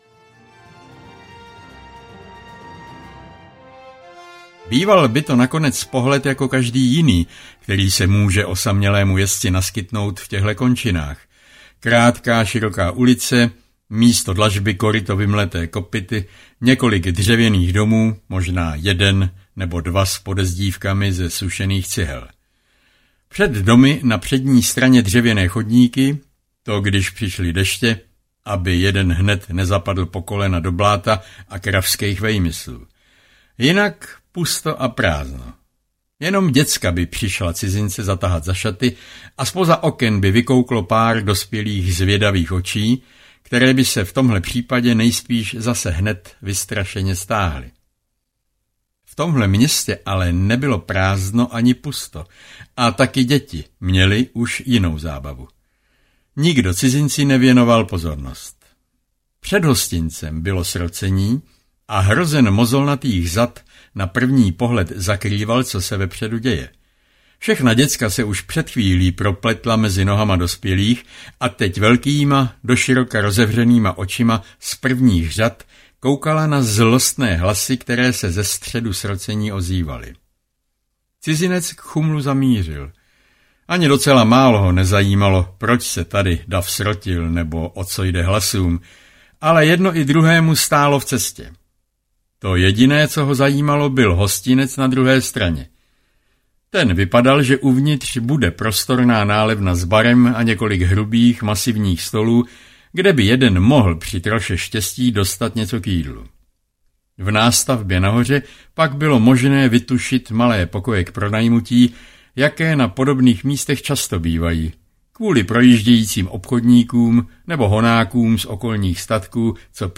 Země bez zákona audiokniha
Ukázka z knihy